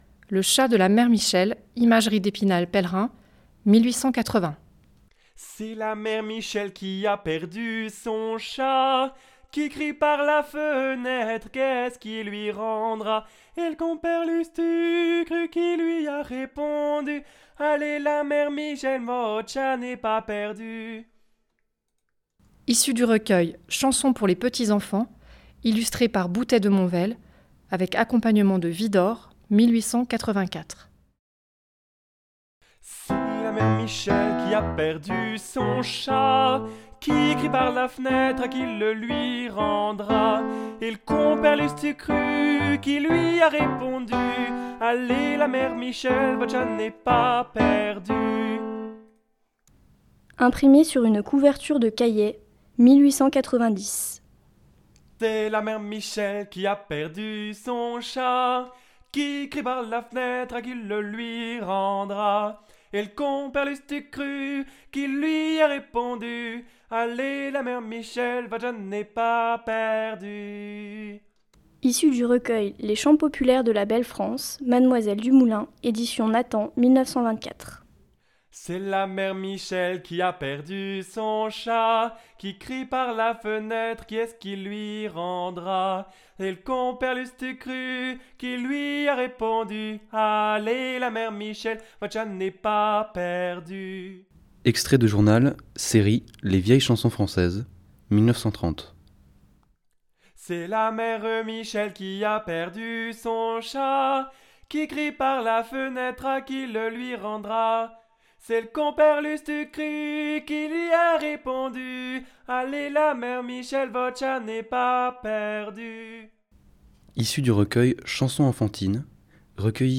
6 On écoutera tout d’abord le remarquable pot-pourri